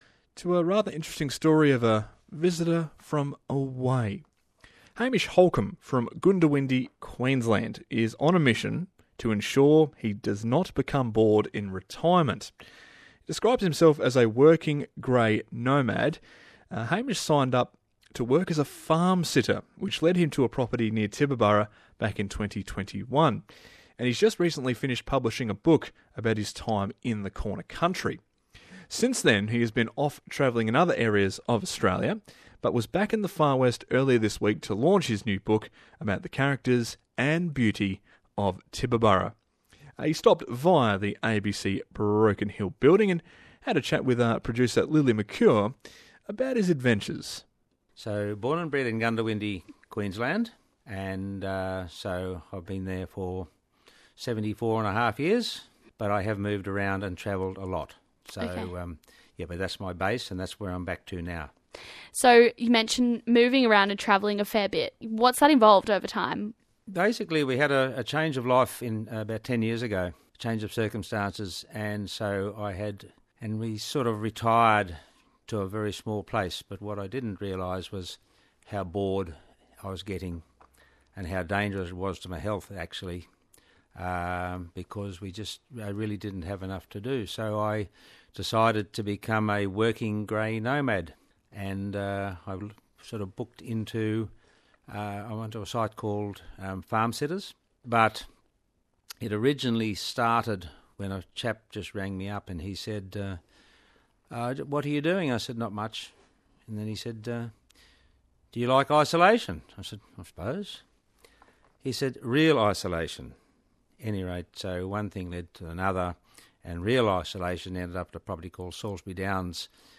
BELOW AN INTERVIEW LINK THAT EXPLAINES VERBALLY, FAR BETTER THAN MY FAT FINGERS SYNDROME HAS THE ABILITY TO DO.